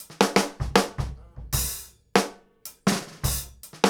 GROOVE 1009R.wav